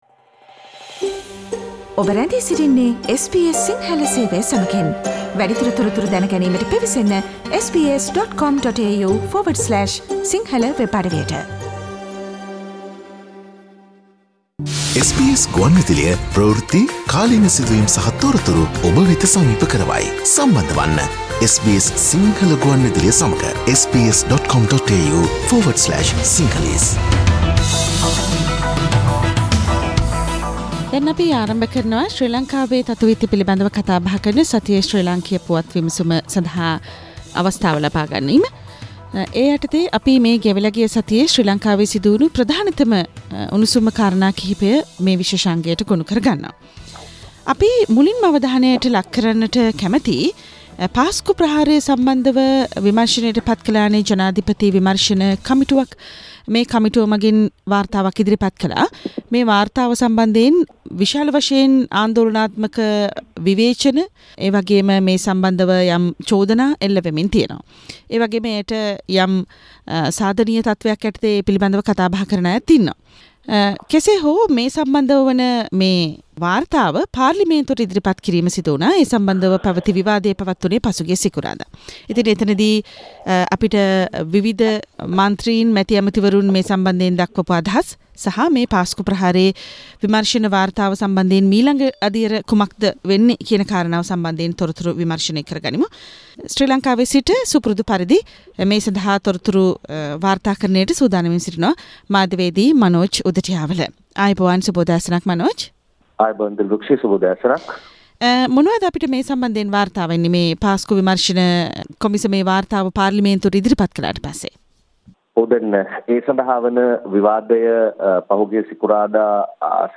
SBS Sinhala radio brings you a comprehensive wrap up of the highlighted news from Sri Lanka with Journalist